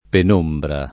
[ pen 1 mbra ]